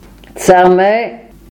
Charmey (French: [ʃaʁmɛ]; Arpitan: Chàrmê [tsɛʁˈmɛ]
Frp-greverin-Tsêrmê.ogg.mp3